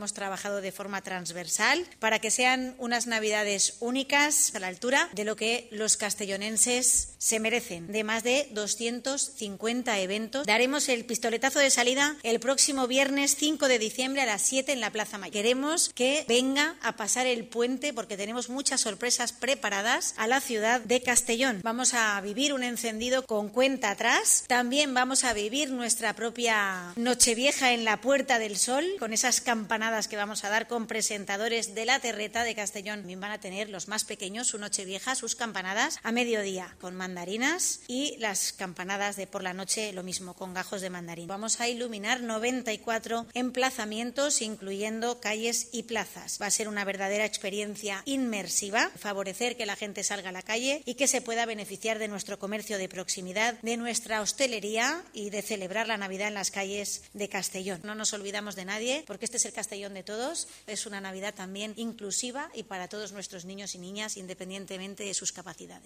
Corte de voz de la alcaldesa de Castellón, Begoña Carrasco.